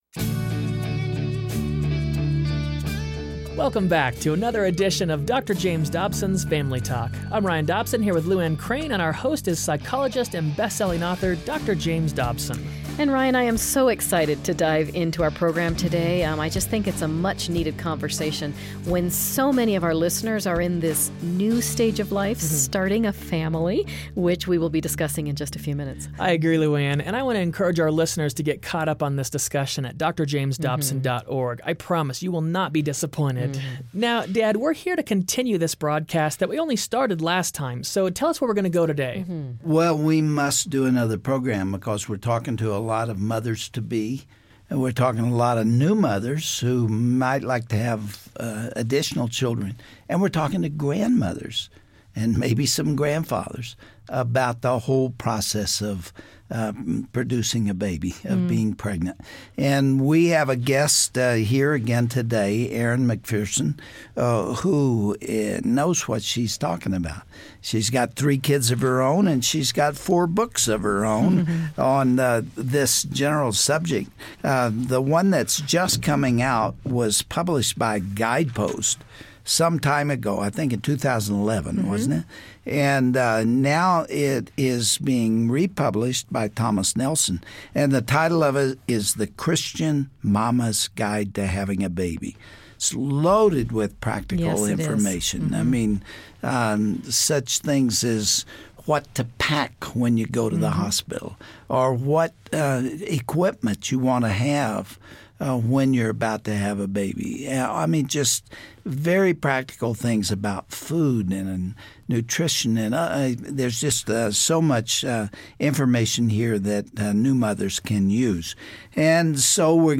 Hear from a woman who faced all of this, and how she and her husband pulled through it together.